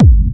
VEC3 Clubby Kicks
VEC3 Bassdrums Clubby 007.wav